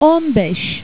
on be,s